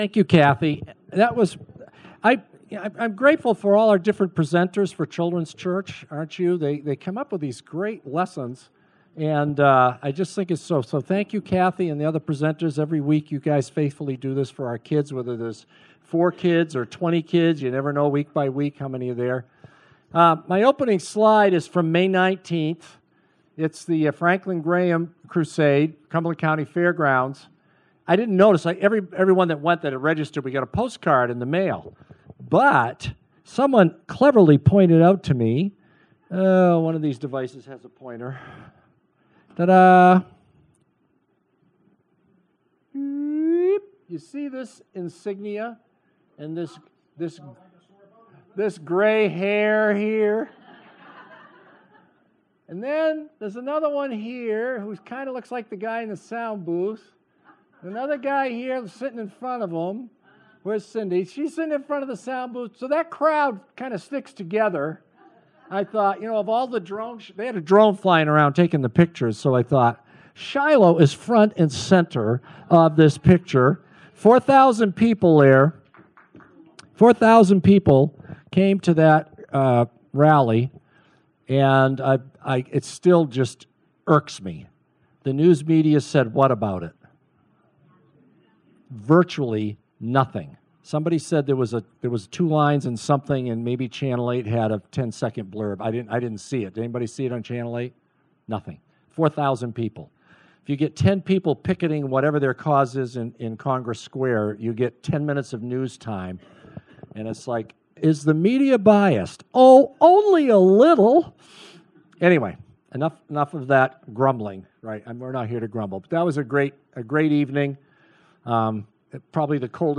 delivers the message